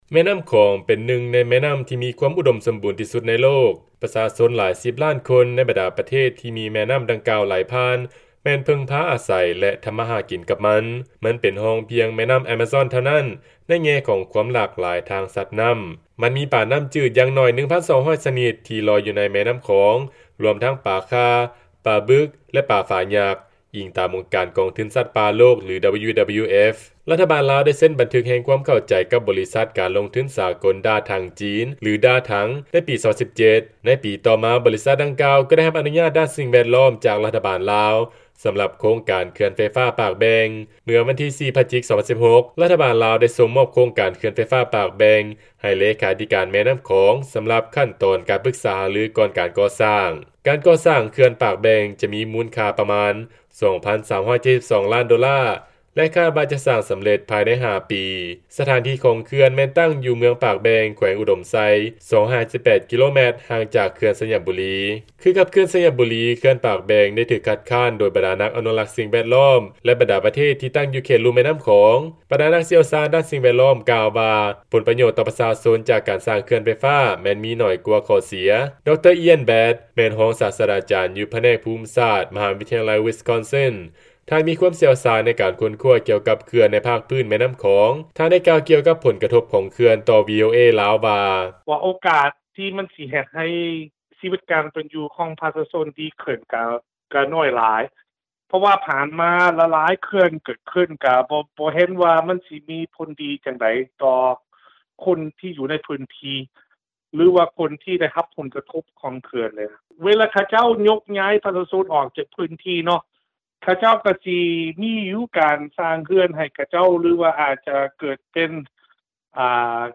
ຟັງລາຍງານ ນັກຊ່ຽວຊານກ່າວວ່າໂຄງການເຂື່ອນປາກແບ່ງ ມີໂອກາດໜ້ອຍຫຼາຍທີ່ຈະເຮັດໃຫ້ຊີວິດການເປັນຂອງປະຊາຊົນໃນທ້ອງຖິ່ນດີຂຶ້ນ